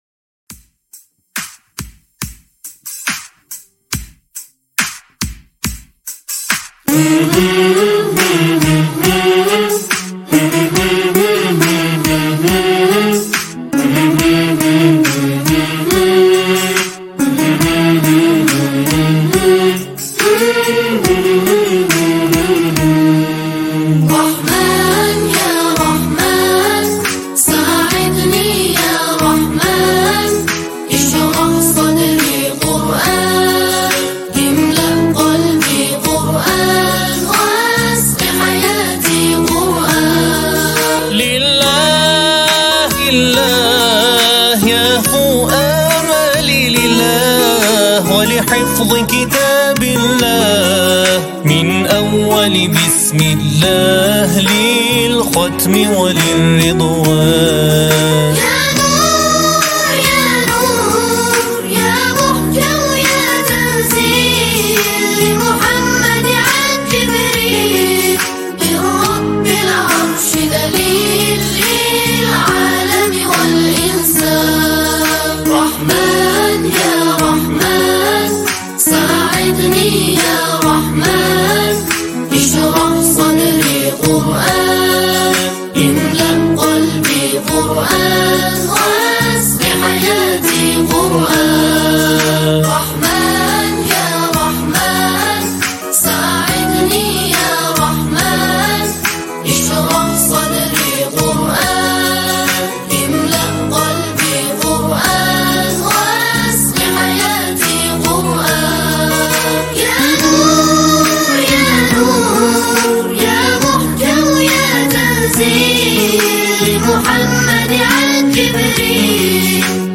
سرود زیبای
سرود رمضانیه